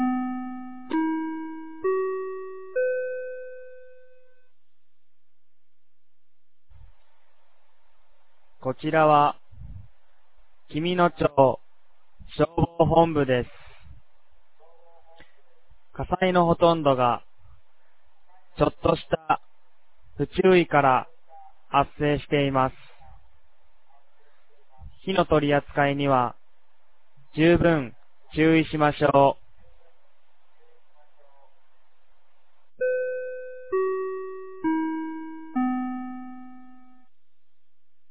2024年02月10日 16時00分に、紀美野町より全地区へ放送がありました。